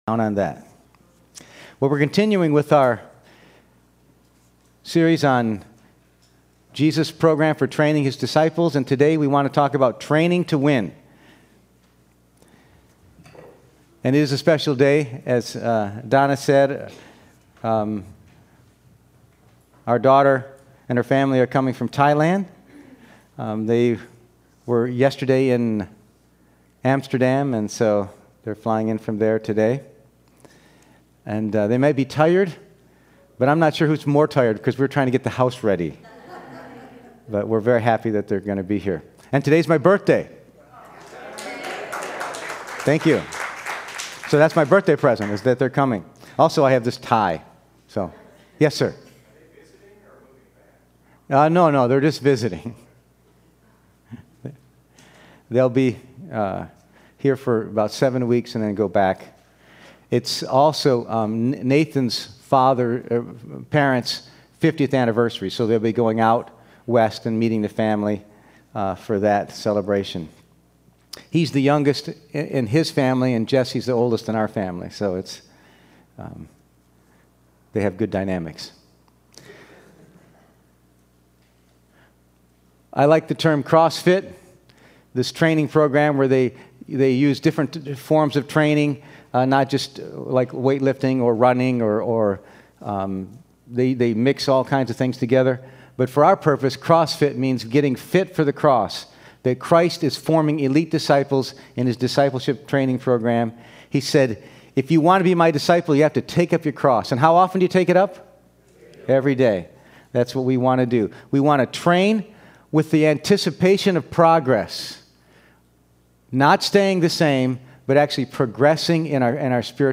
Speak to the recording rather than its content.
Passage: 1 Corinthians 9:24-27, 1 Timothy 6:12, 2 Timothy 4:7 Service Type: Sunday Morning